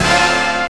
JAZZ STAB 4.wav